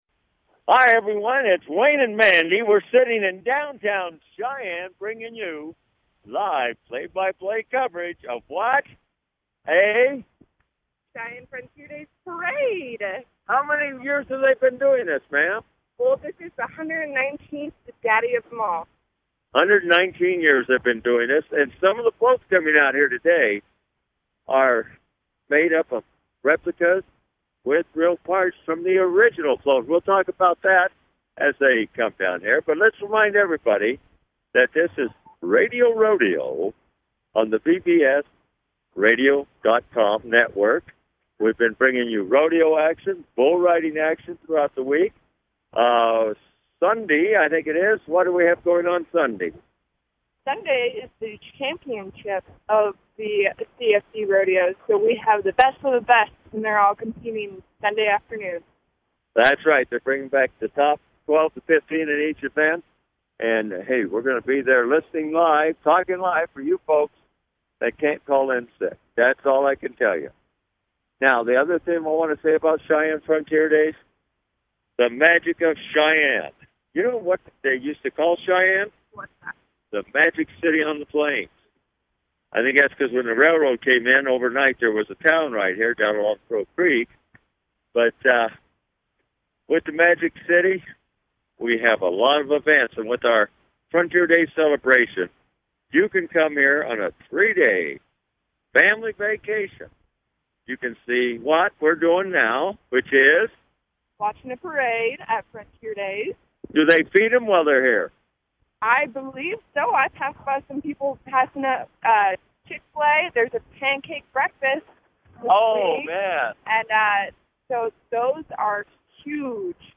Live play by play rodeo experiences!